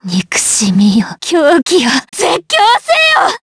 DarkFrey-Vox_Skill2_jp.wav